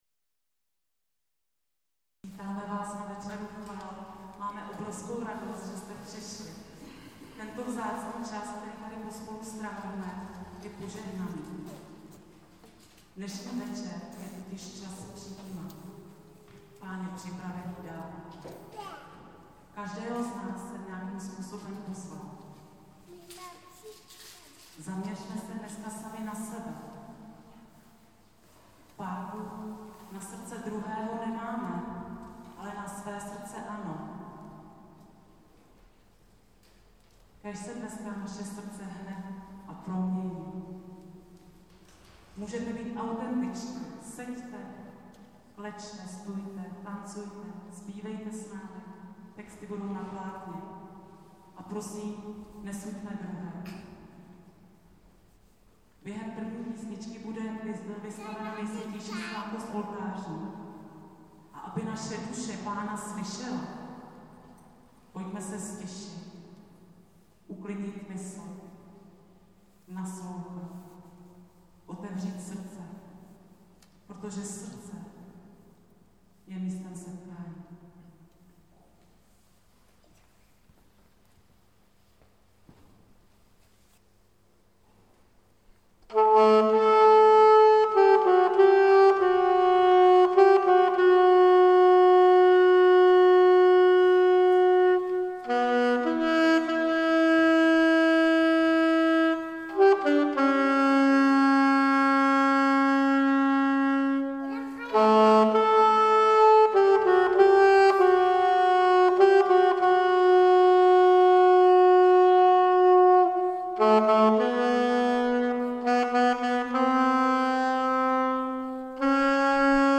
Audiozáznam z 18. večera chval Tentokrát bylo téma uzdravení.
A na závěr radostná píseň Freedom is coming – přichází radost a osvobození od balastu neodpuštění a přichází svoboda.
Večer chval s kapelou Výbuch